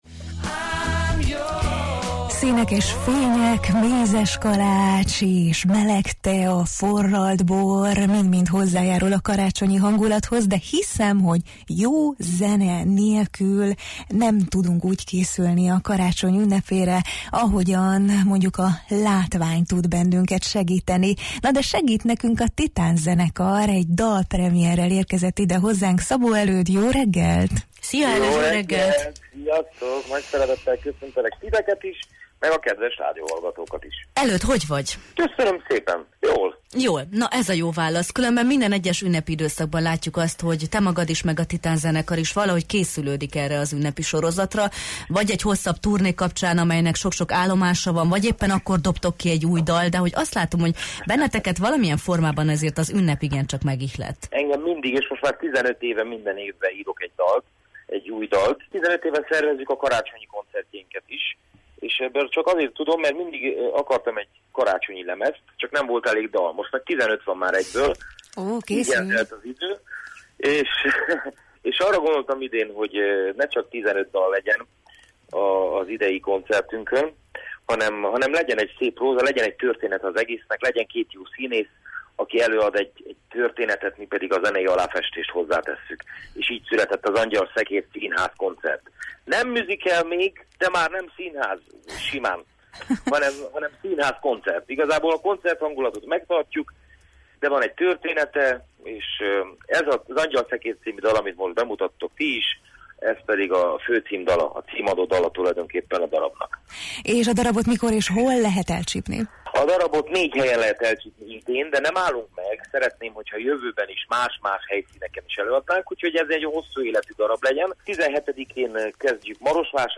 dalszerzővel beszélgettünk a Jó reggelt